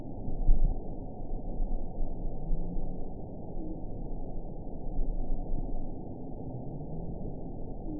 event 922857 date 04/22/25 time 21:29:22 GMT (1 month, 3 weeks ago) score 8.84 location TSS-AB10 detected by nrw target species NRW annotations +NRW Spectrogram: Frequency (kHz) vs. Time (s) audio not available .wav